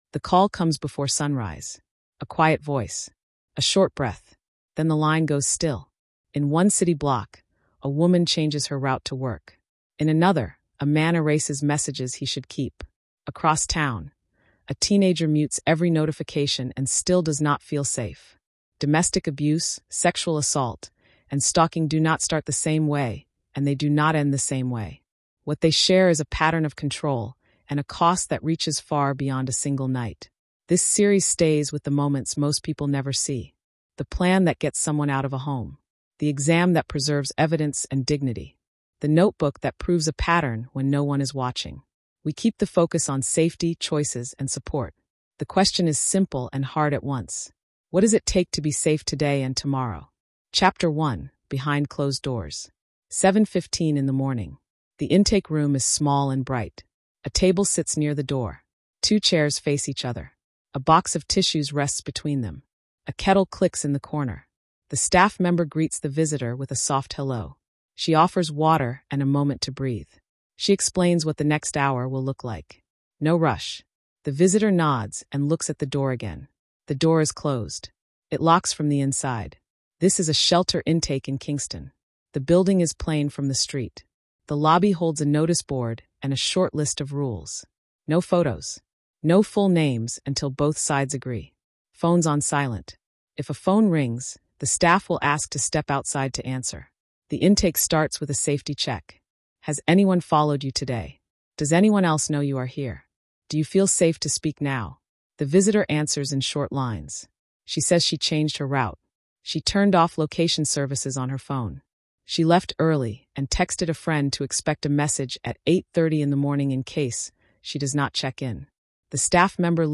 A clear, unflinching documentary in three chapters that shows how safety is built step by step after domestic abuse, sexual assault, and stalking. We follow the moments most people never see: shelter intake, the forensic exam, the court day, and the long work of documenting a pattern. The tone is calm and respectful. The focus is survivor choice, practical safety, and community action.